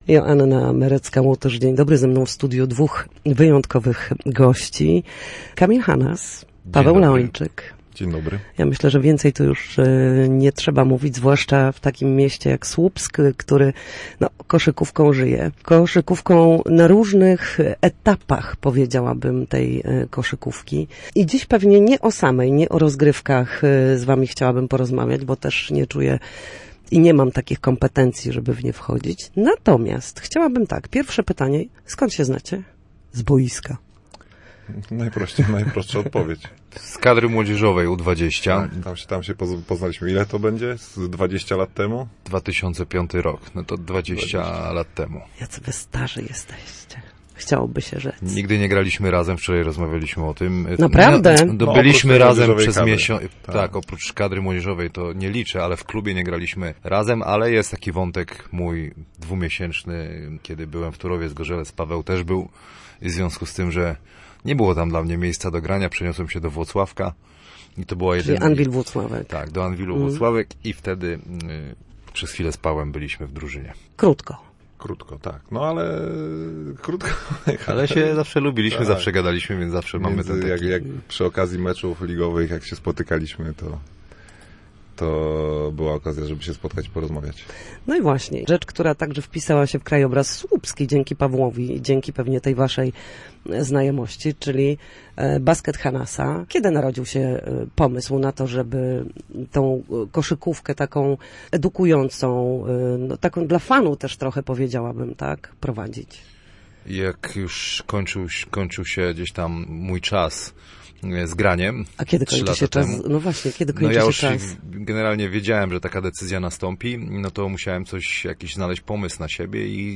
Studiu Słupsk Radia Gdańsk
koszykarze przekazujący swoją wiedzę i pasję kolejnym pokoleniom